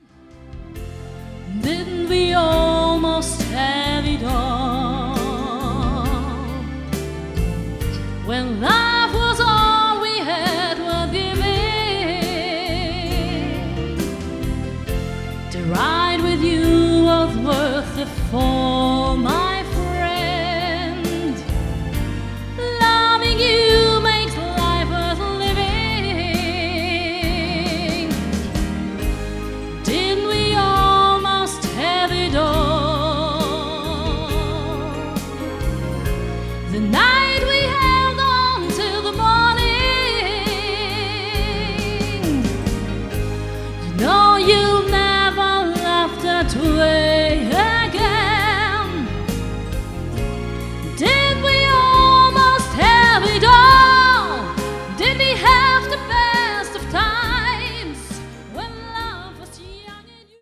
(Swing)